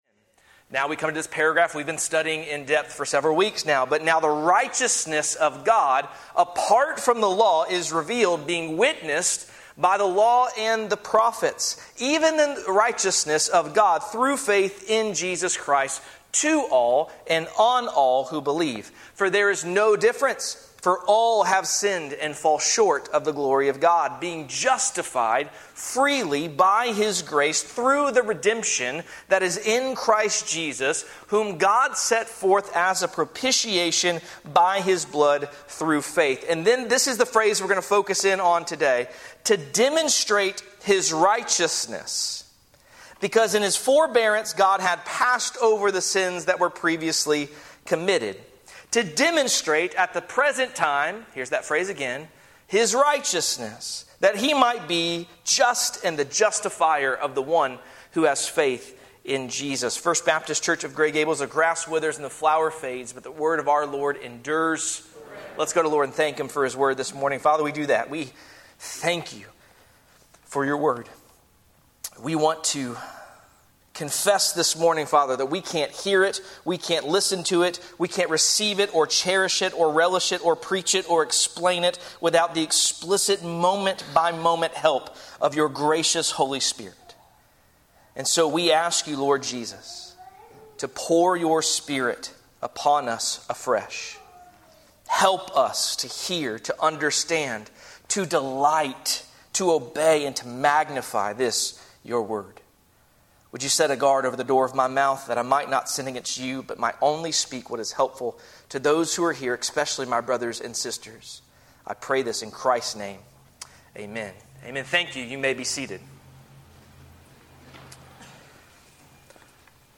Sermons | First Baptist Church of Gray Gables